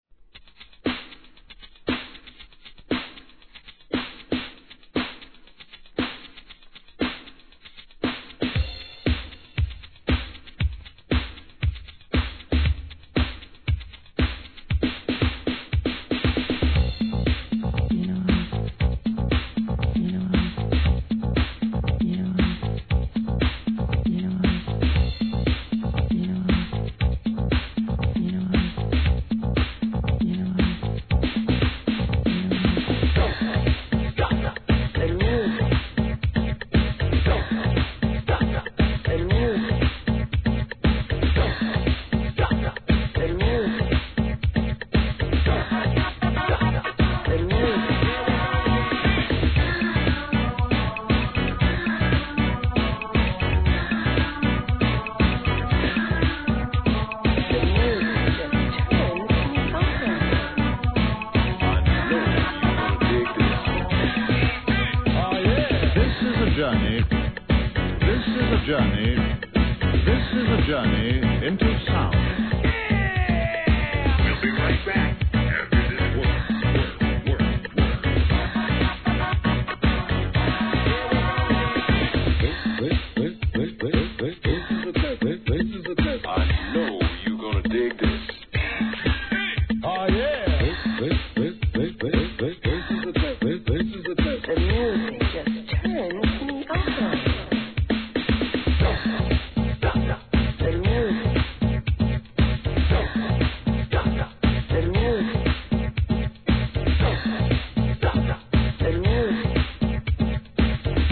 HIP HOP/R&B
'80s HIP HOUSE調のエレクトロOLD SCHOOL!!